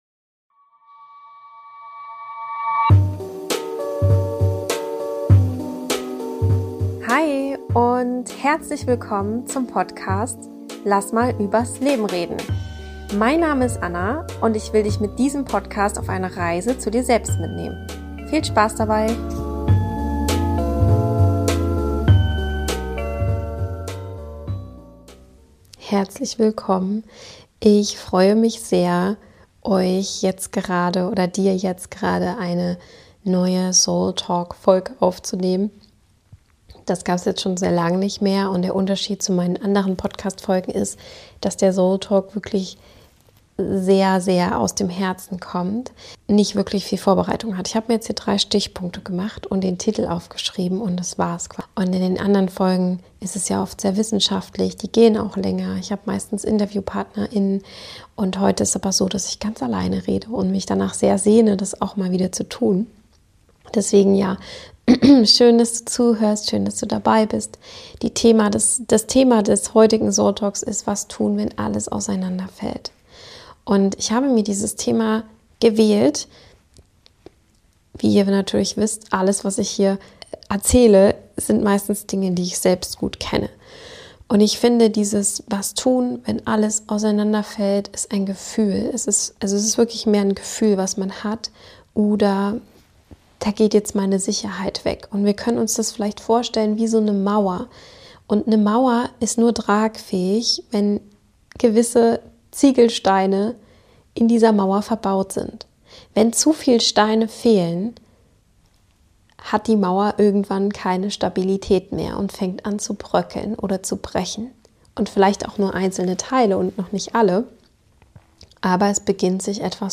Manchmal bricht das Leben einfach auseinander: ein Verlust, ein Ende, eine Veränderung, die alles erschüttert. In dieser Solo-Folge spreche ich ganz ehrlich darüber, wie es sich anfühlt, wenn nichts mehr Halt gibt, und was in solchen Momenten wirklich hilft.